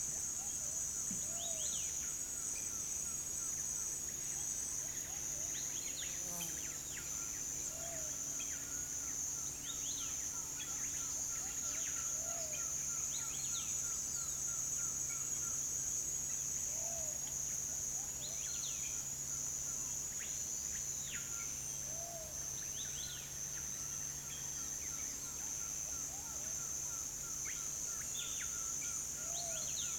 rainforest
bird-voices